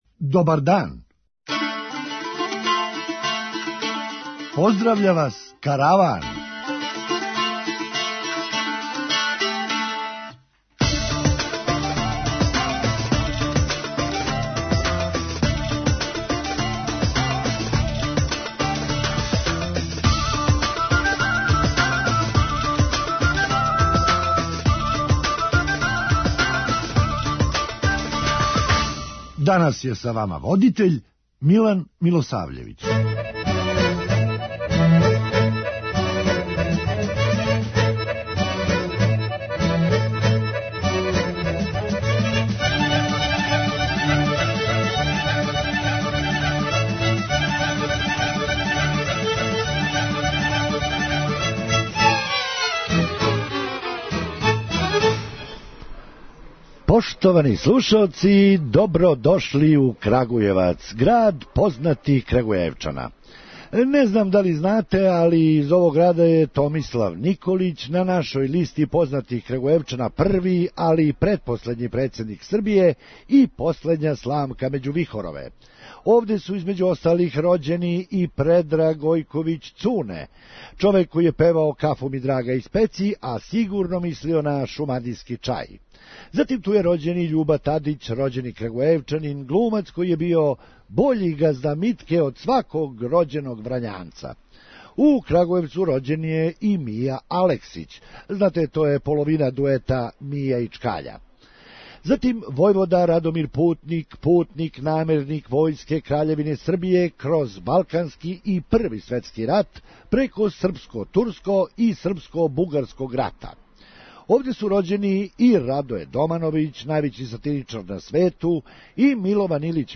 Хумористичка емисија
Очевици кажу да је толико лоше возила да јој је глас са навигације рекао: ''Након 50 метара станите да ја изађем!'' преузми : 8.84 MB Караван Autor: Забавна редакција Радио Бeограда 1 Караван се креће ка својој дестинацији већ више од 50 година, увек добро натоварен актуелним хумором и изворним народним песмама.